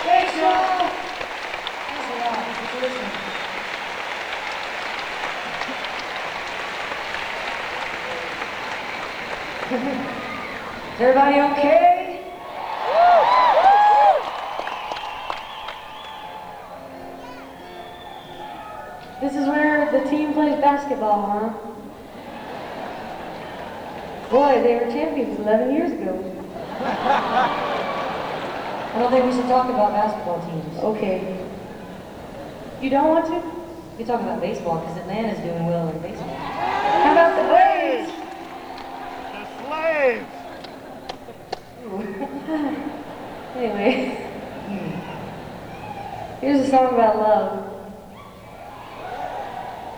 lifeblood: bootlegs: 1991-09-01: seattle center coliseum - seattle, washington (alternate recording - 24 bit version)
(acoustic duo)
05. talking with the crowd (0:50)